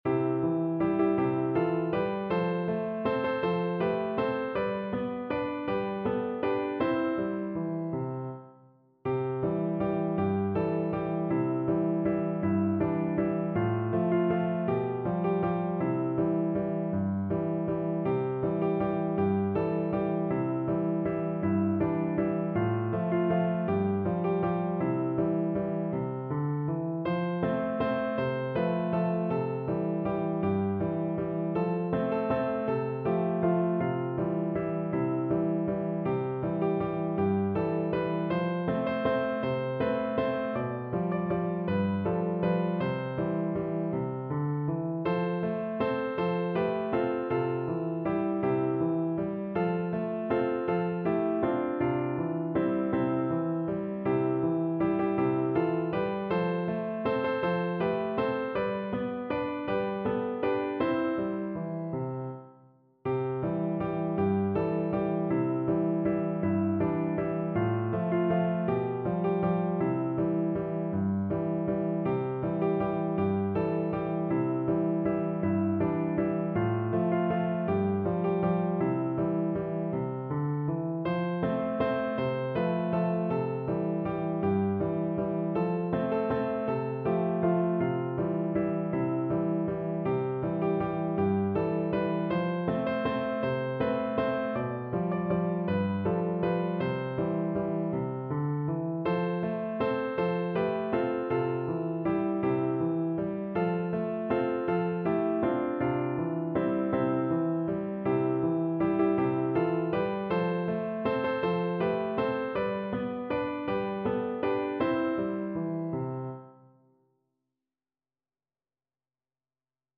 3/4 (View more 3/4 Music)
One in a bar =c.160
D5-D6